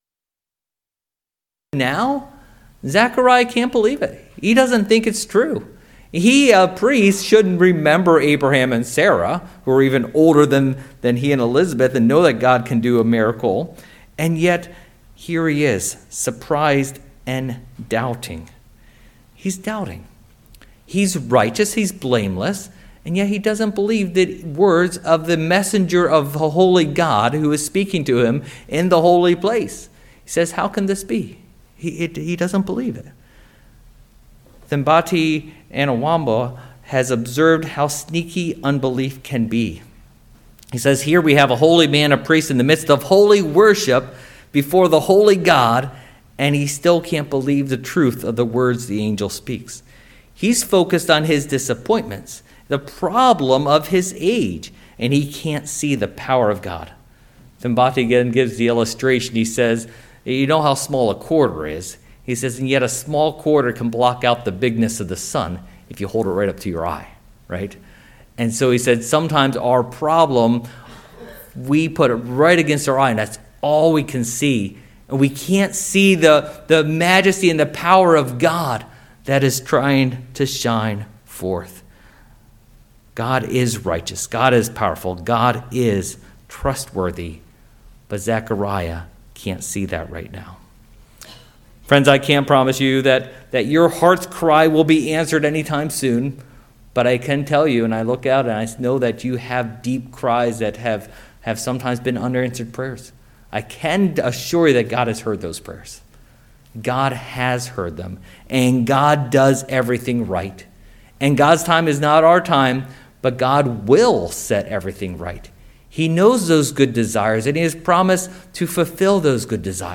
Service Type: Advent